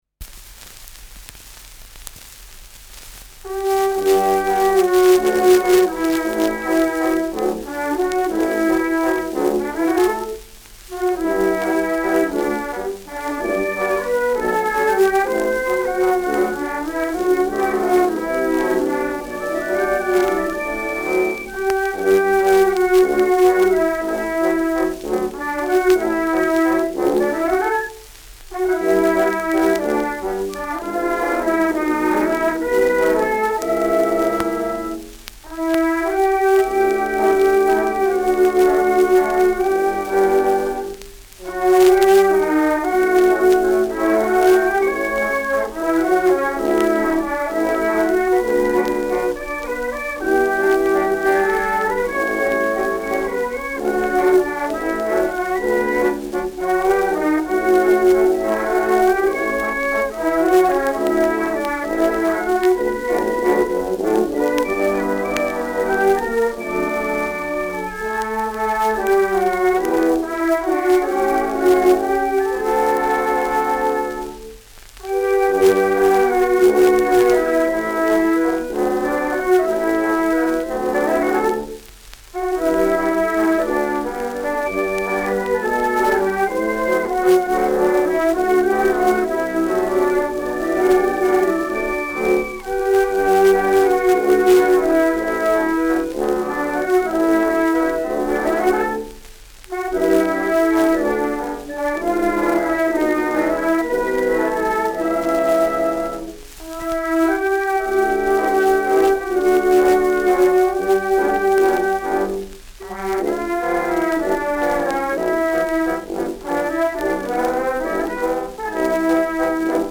Schellackplatte
[Nürnberg] (Aufnahmeort)